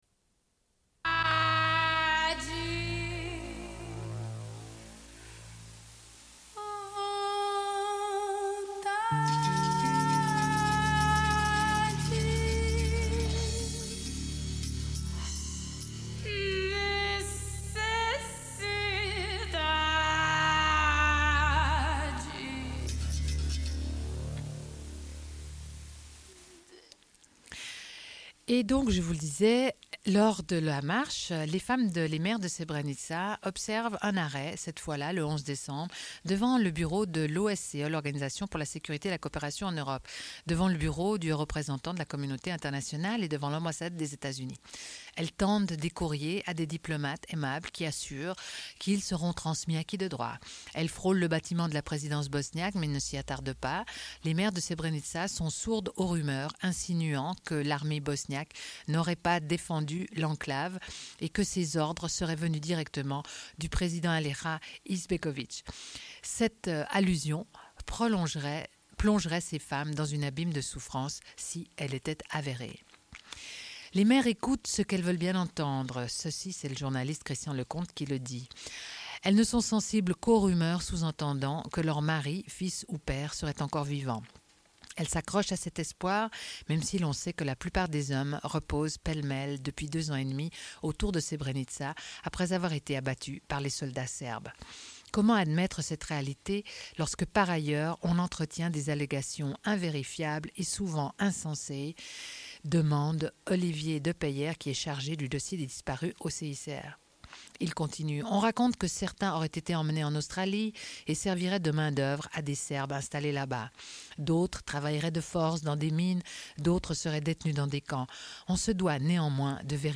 Suite de l'émission : présentations d'événements en lien avec les femmes à Genève. Émission écourtée, le Bulletin d'information de Radio pleine lune commence à la fin de la cassette (27:15).